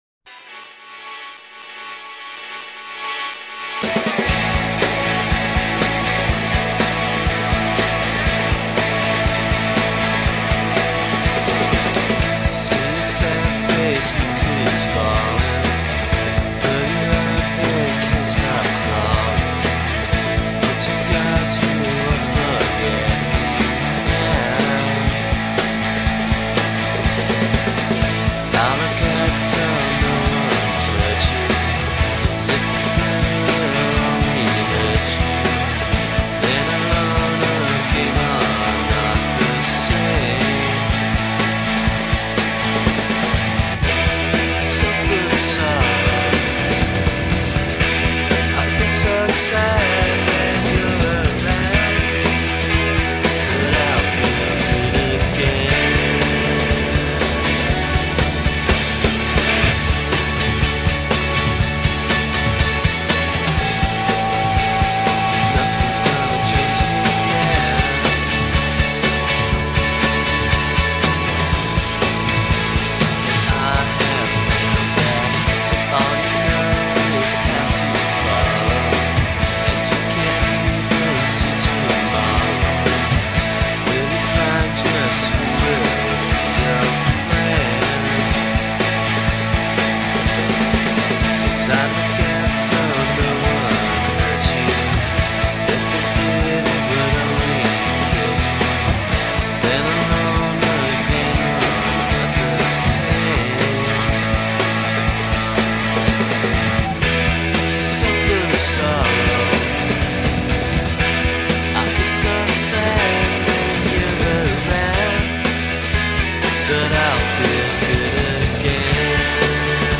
Part of the UK shoegaze scene of the early 90’s